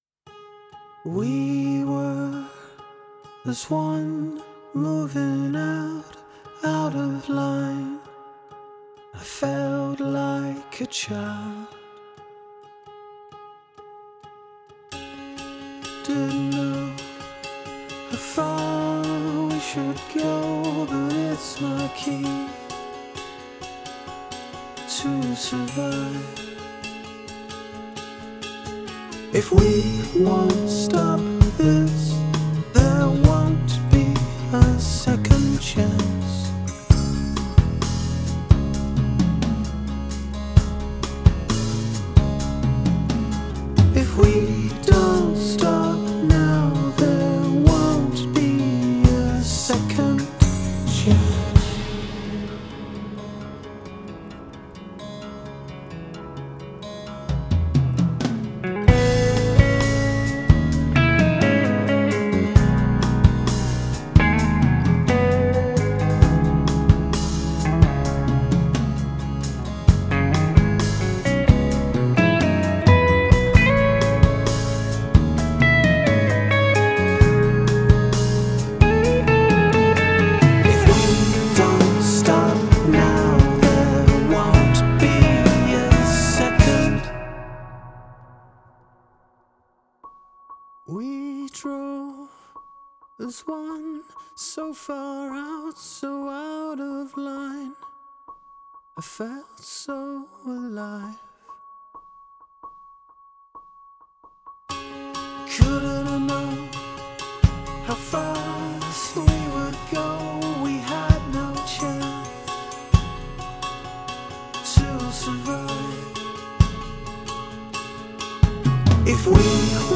Genre: progressive rock
ریتمشو دوست دارم ، آرومه